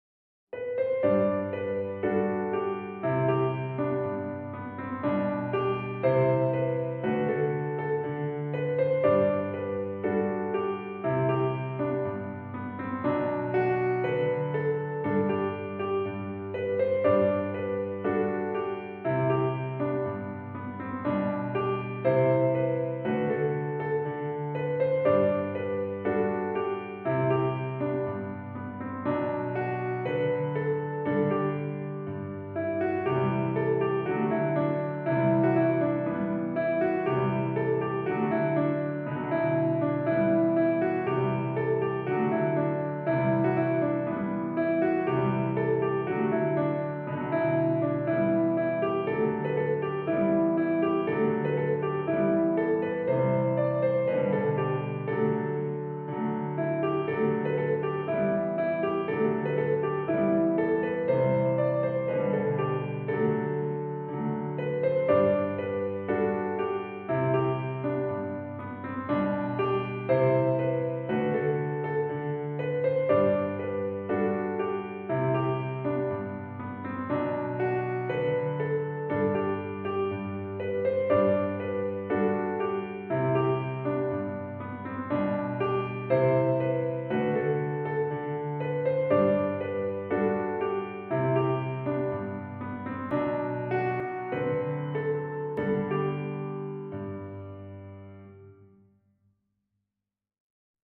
piano sheet music
for piano